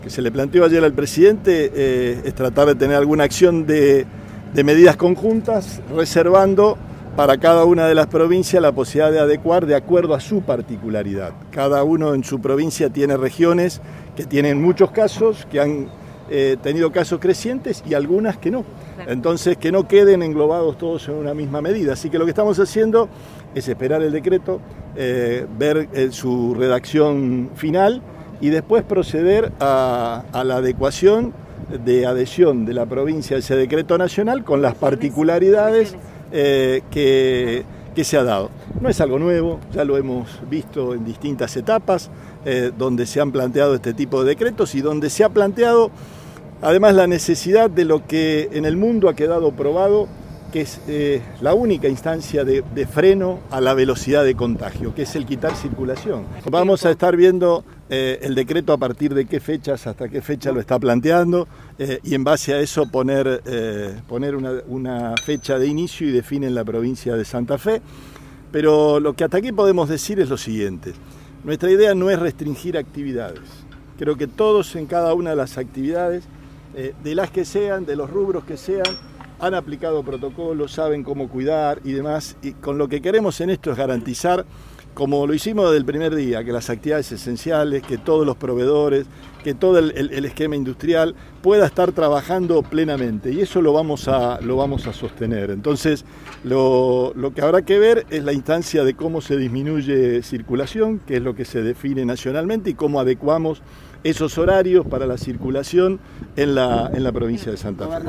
Las declaraciones del gobernador